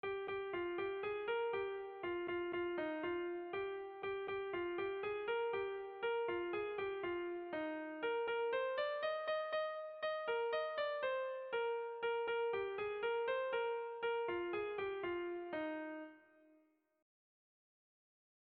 Erlijiozkoa
Elizetan jaunartzerakoan kantatzen da.
A1A2BA3